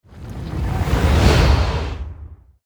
warrior_skill_flyingswing_01_charge.ogg